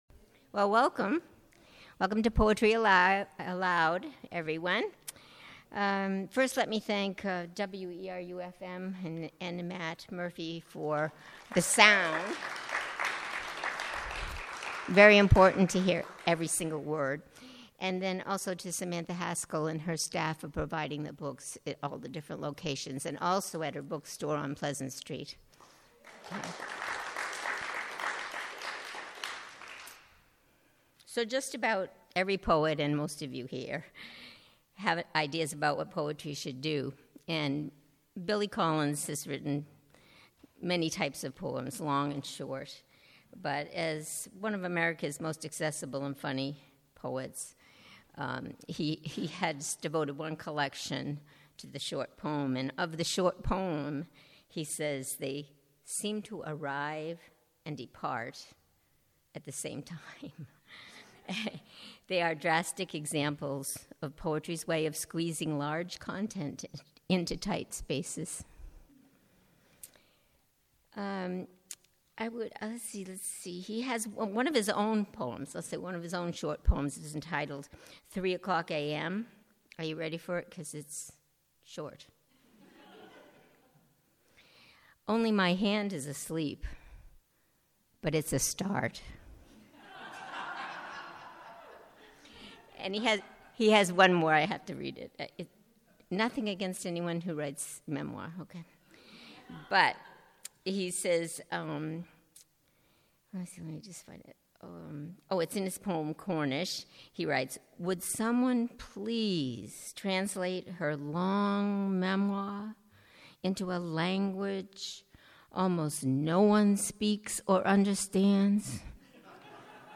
The power of community supporting the art of poetry is ever present at Word’s annual POETRY ALIVE event. It’s an ideal opportunity to spend time with a variety of poetic voices.